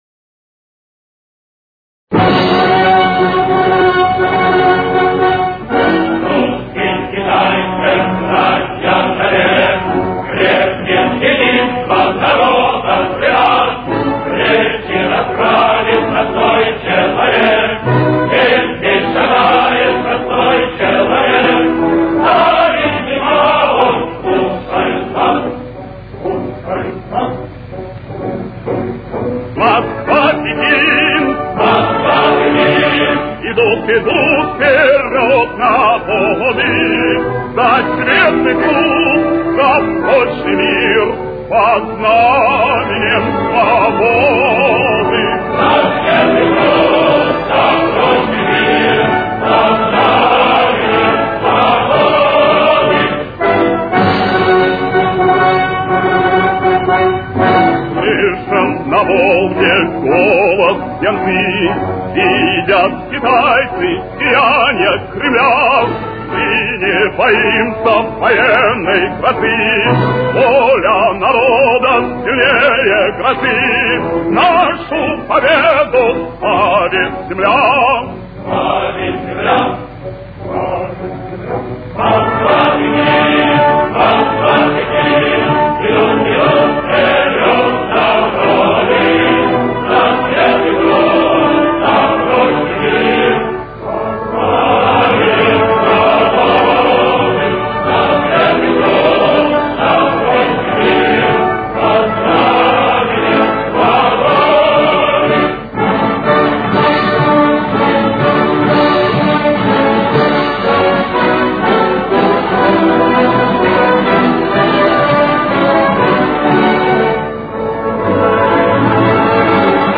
Темп: 124.